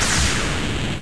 サンプリングしたものや他の方から提供されたものではない，全てシンセで新規作成したデータですので，著作権の心配なく自由に使用できます。
（効果音作成に使用したシンセ。　YAMAHA V50,TG-500,SY99。その他エフェクター類）
粉砕！！